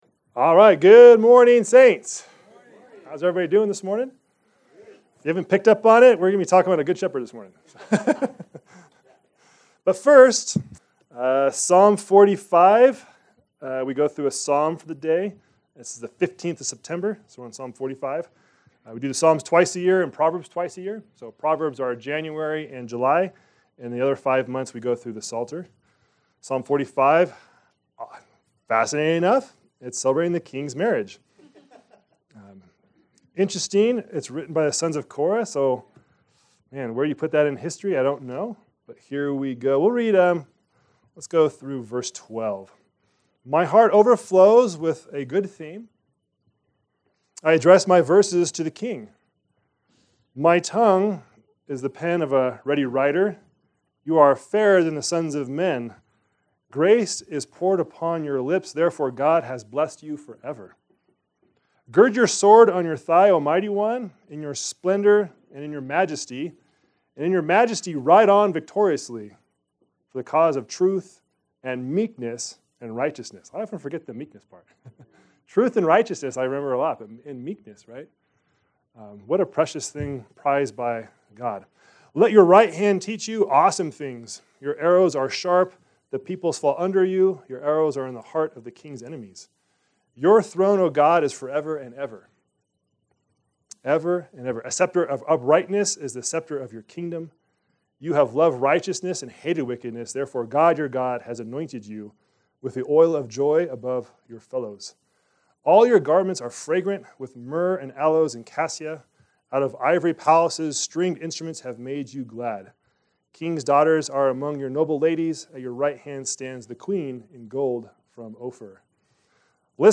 Our Sermons